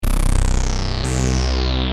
Free MP3 vintage Sequential circuits Pro-600 loops & sound effects 2
Sequencial Circuits - Prophet 600 46